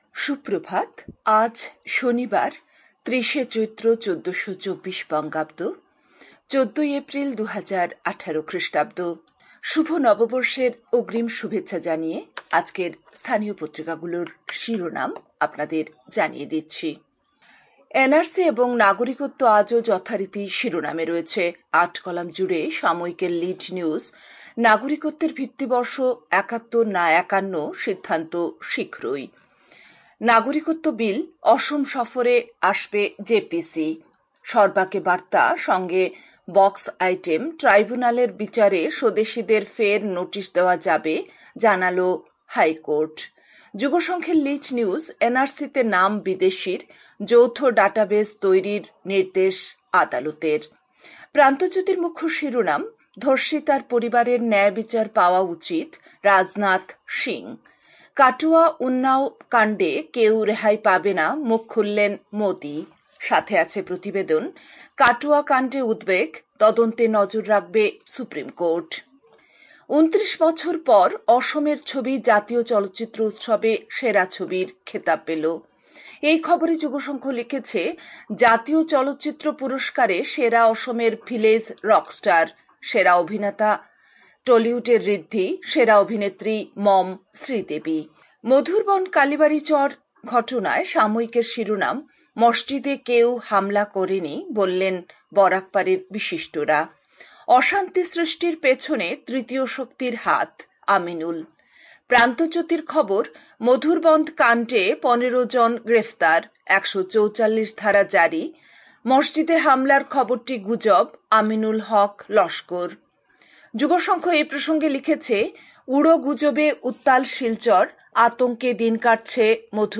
A quick bulletin with all top news.